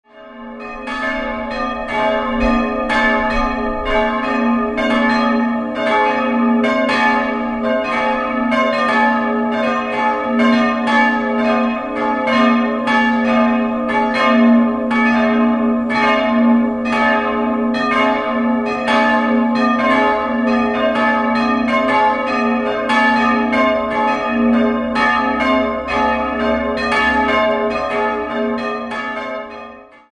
3-stimmiges Geläute: as'-c''-es''